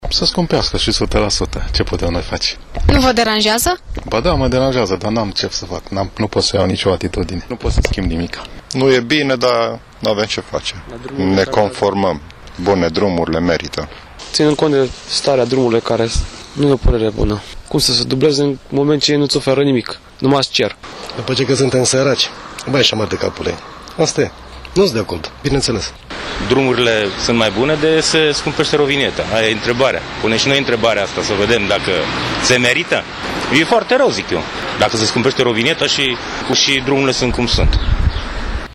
Şoferii reşiţeni s-au arătat afectaţi de noul proiect: